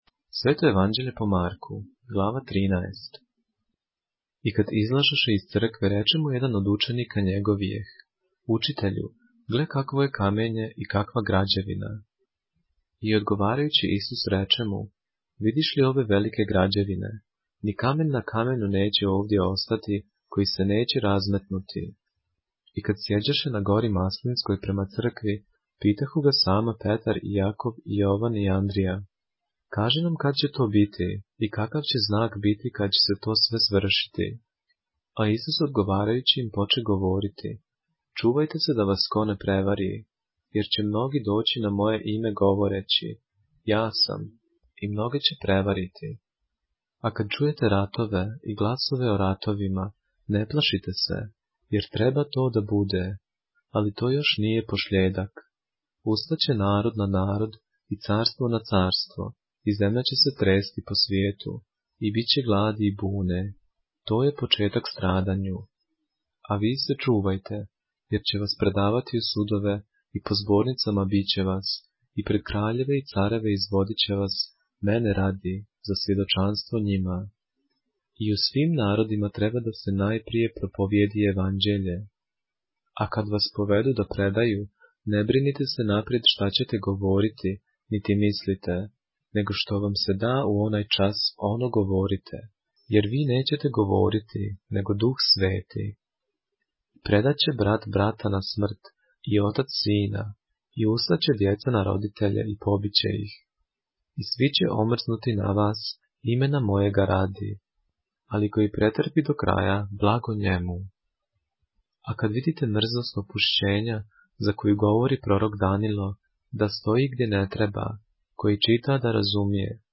поглавље српске Библије - са аудио нарације - Mark, chapter 13 of the Holy Bible in the Serbian language